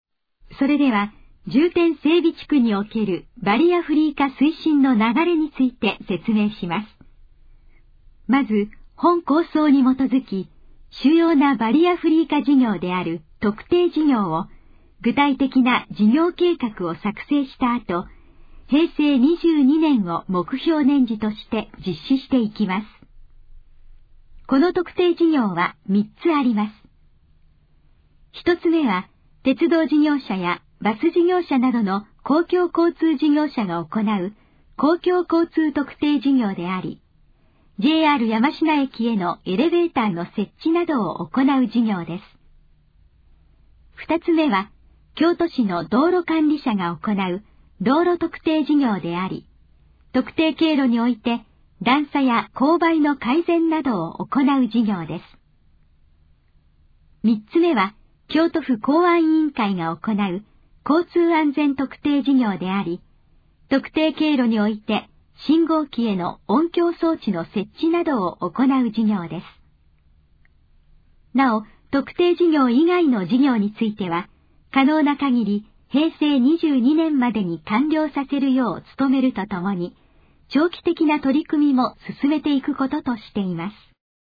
このページの要約を音声で読み上げます。
ナレーション再生 約169KB